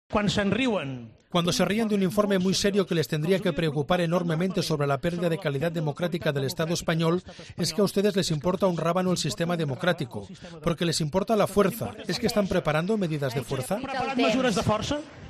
El presidente de la Generalitat ha lanzado esta pregunta durante la sesión de control al Gobierno catalán